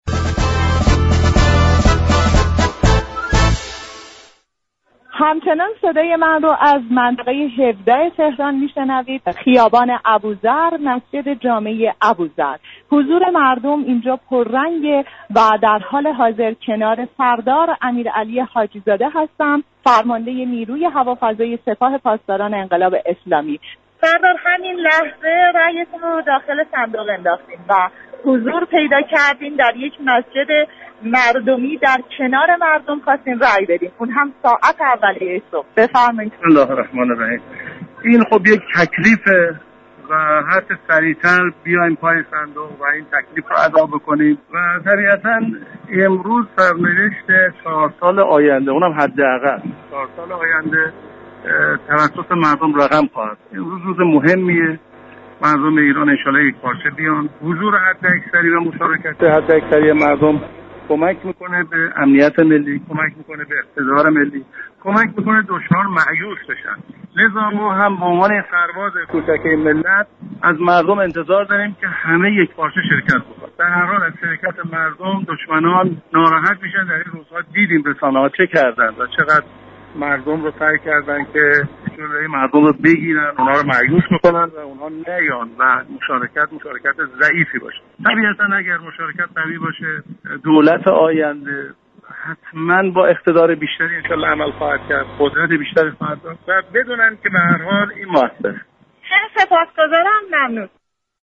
گفت و گوی اختصاصی